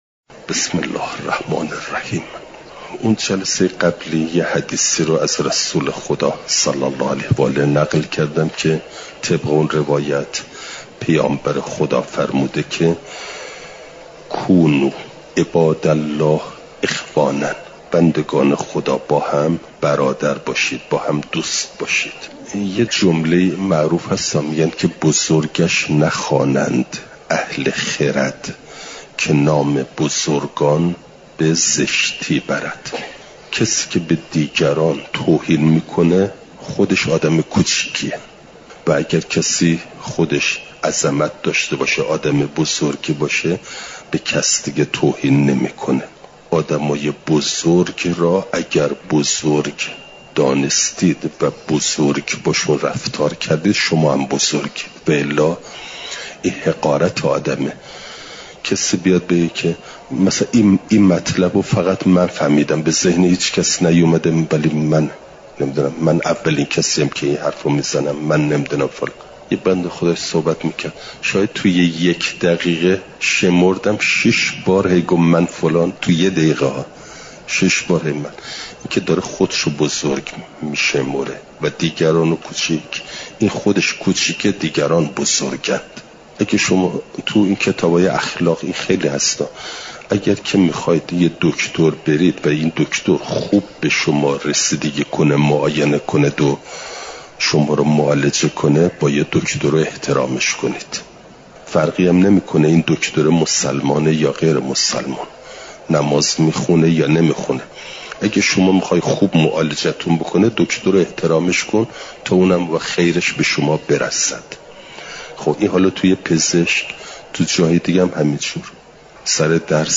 چهارشنبه ۱۶ مهرماه ۱۴۰۴، حرم مطهر حضرت معصومه سلام ﷲ علیها